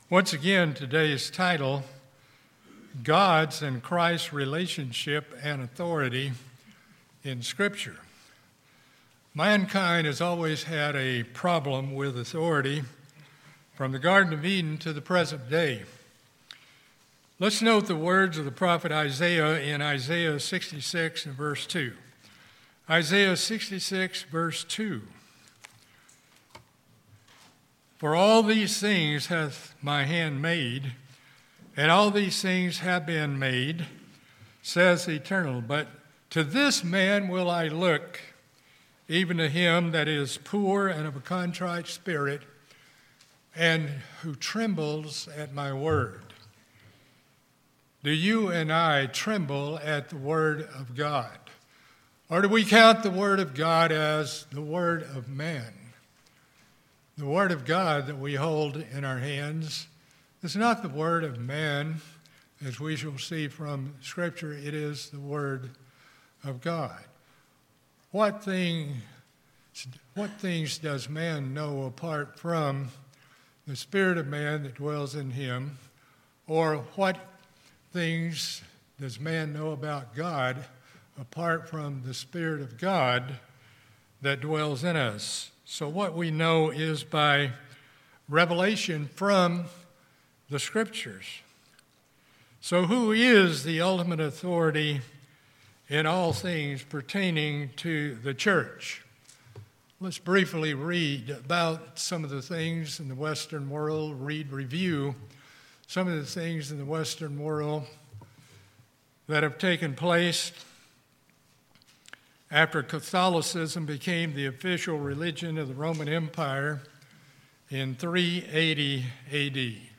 In this sermon we examine scriptures that reveal the relationship and authority of God the Father and His Son Jesus Christ.